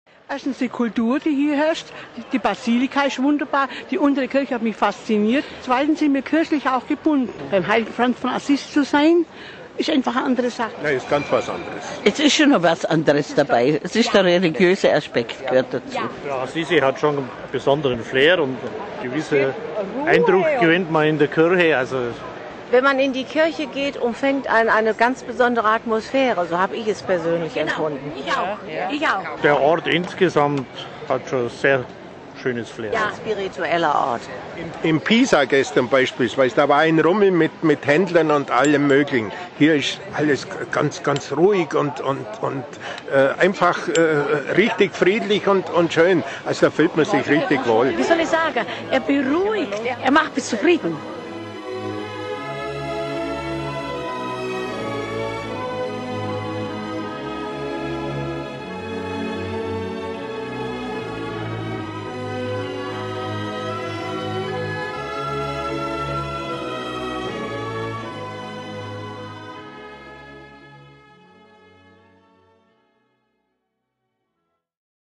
Heute heißt sie „Stadt des Friedens“, und immer wieder ist die Rede von dem besonderen Geist Assisis. Was steckt dahinter, hören Sie deutsche Pilger und ihre Eindrücke.